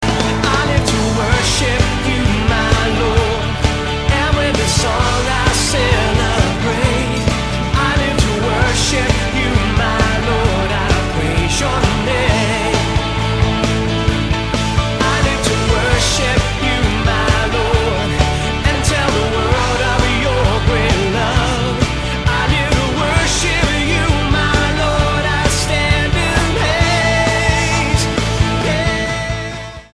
STYLE: Pop
are rather spoilt by over-excitable congregational clapping